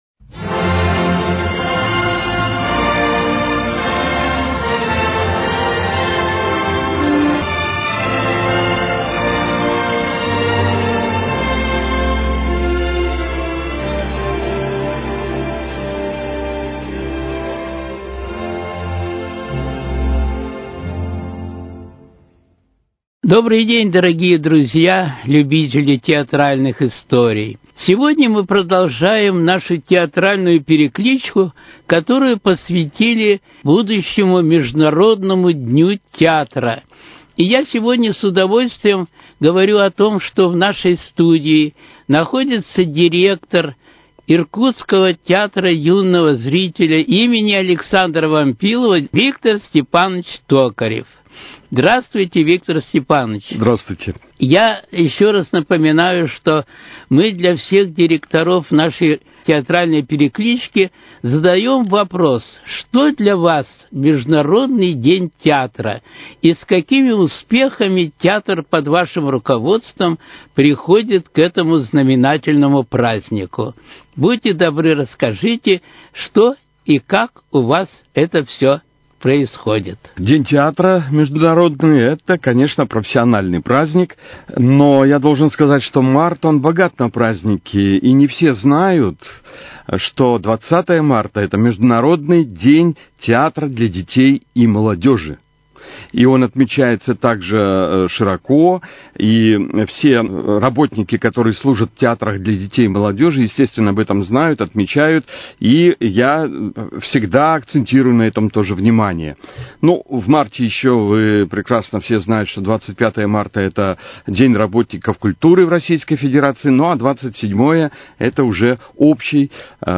Театральные истории: Беседа